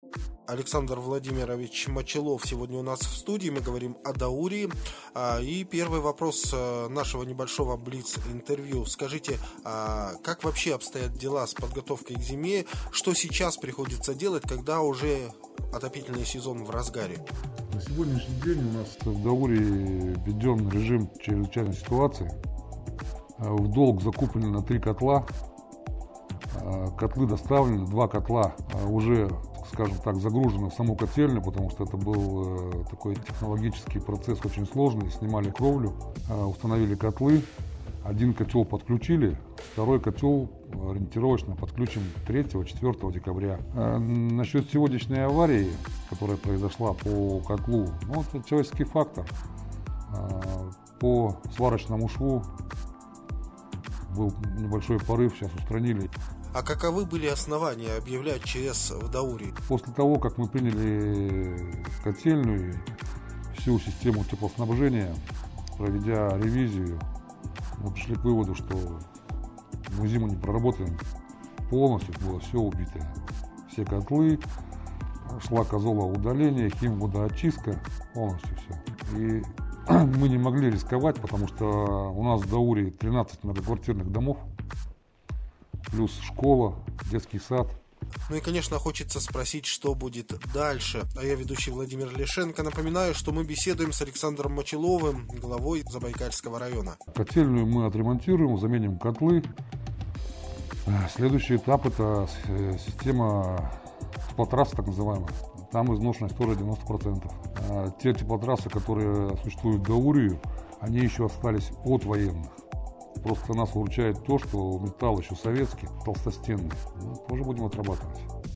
О ситуации, связанной с вопросами теплоснабжения, рассказывает глава Забайкальского района Александр Мочалов.
БЛИЦ-ОПРОС-АЛЕКСАНДР-МОЧАЛОВ-ГЛАВА-ЗАБАЙКАЛЬСКОГО-РАЙОНА-О-СИТУАЦИИ-В-ДАУРИИ.mp3